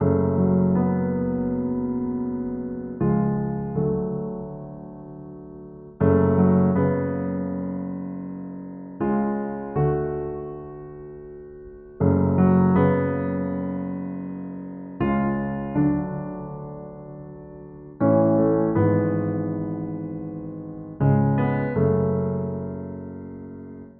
Dark Keys 5 BPM 80.wav